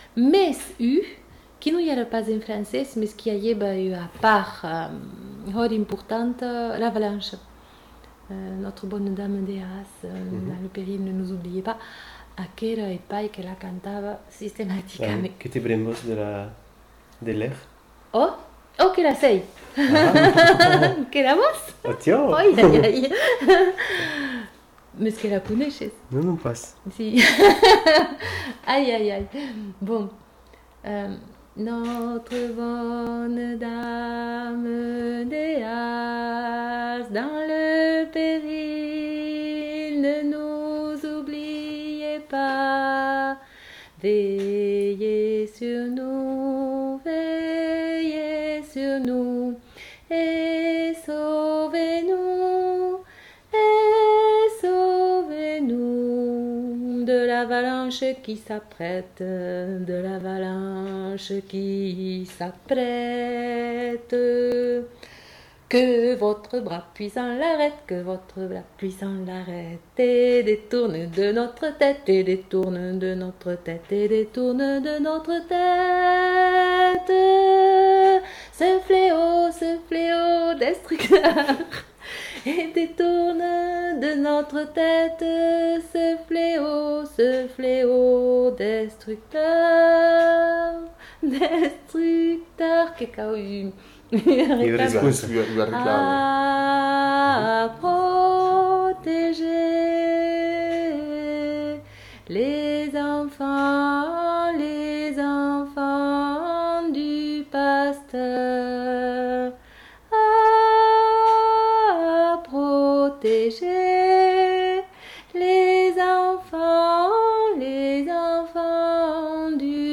Aire culturelle : Bigorre
Lieu : Ayzac-Ost
Genre : chant
Effectif : 1
Type de voix : voix de femme
Production du son : chanté
Classification : religieuses diverses